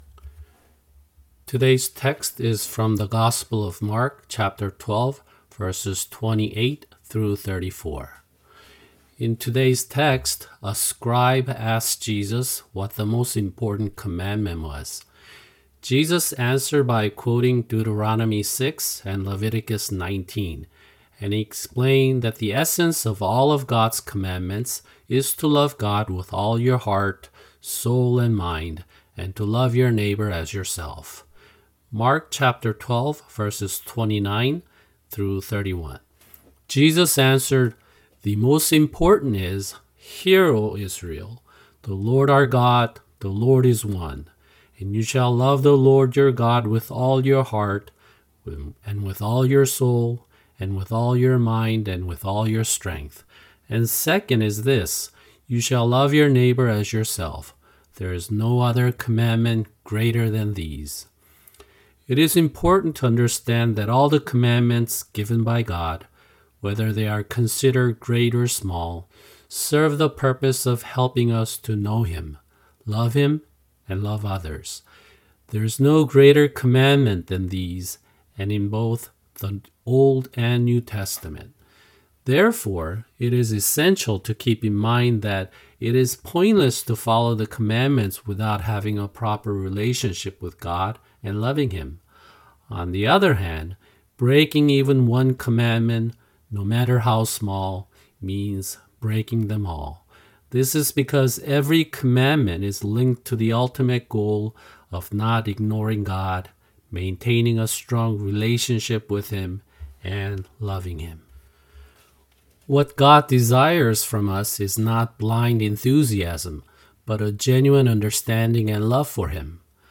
[주일 설교] 마가복음(57) 12:28-34(2)